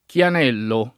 [ k L an $ llo ]